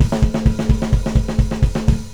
Black Metal Drum Rudiments
Beat 1 - The Thrash Beat
Each of these patterns is written in sixteenth note increments
Hat/Ride x x x x x x x x x x x x x x x x
thrash1.wav